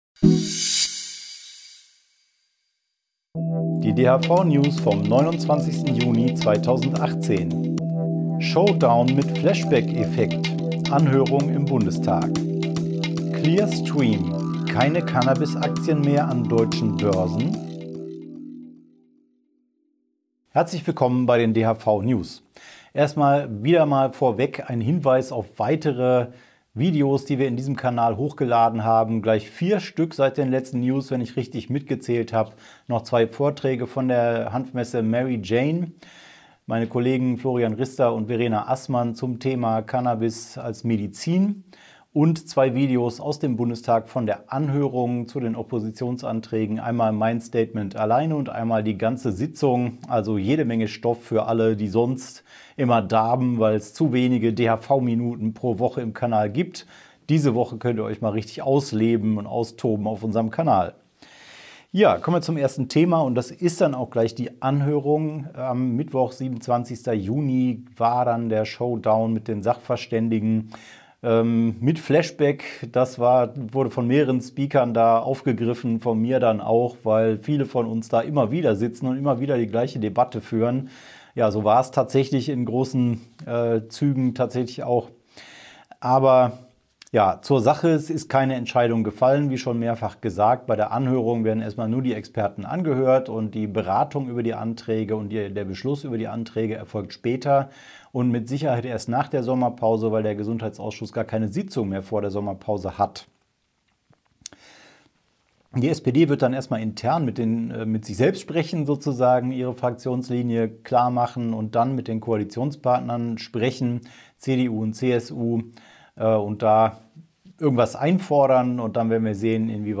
DHV-Video-News #171 Die Hanfverband-Videonews vom 29.06.2018 Die Tonspur der Sendung steht als Audio-Podcast am Ende dieser Nachricht zum downloaden oder direkt hören zur Verfügung.